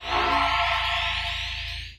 vibrate.wav